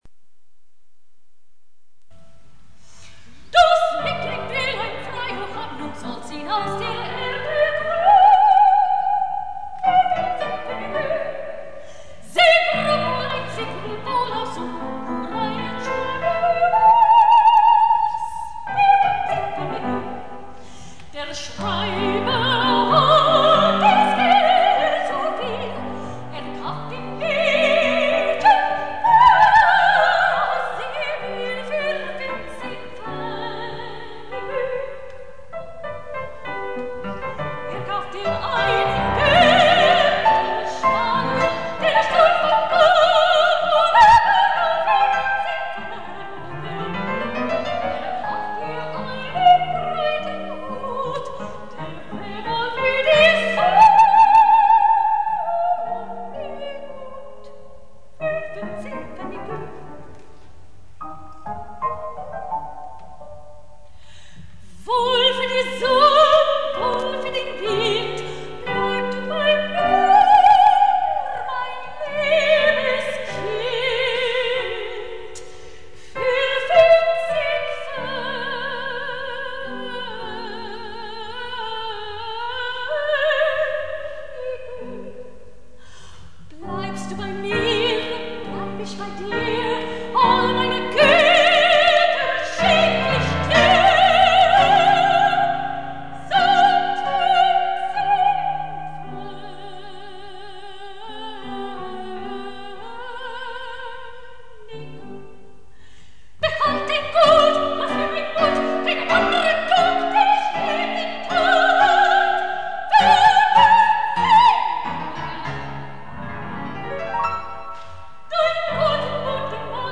RECITAL  PRESTIGO
soprano
au piano